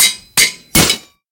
anvil_break.ogg